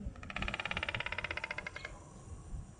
东方白鹳鸣肌退化无声带 通过上下喙碰撞发出声音